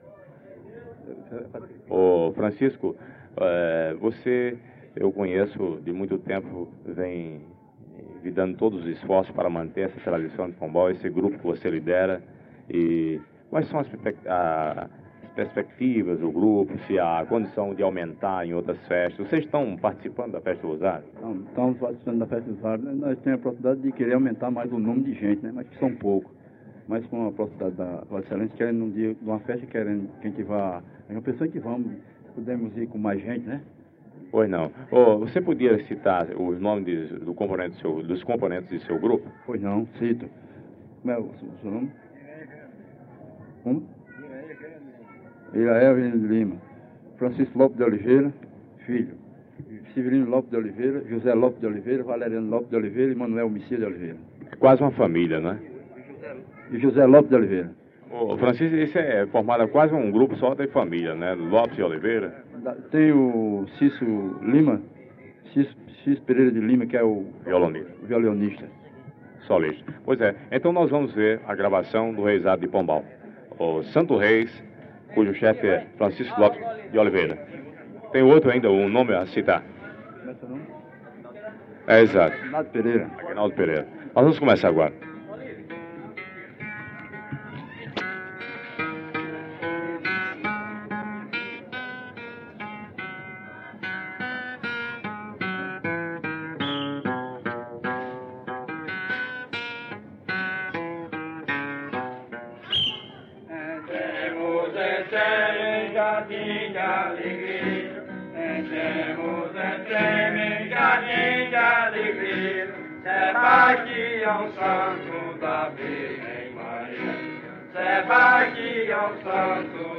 Reisado
AFMI 04 Pombal 01/10/1972 Pontões da Festa de Nossa Senhora do Rosário. 6 trechos musicais com pífano, fole de oito baixos, maracás e pratos (a quinta parece um caboré), gravados pela rádio Correio da Paraíba.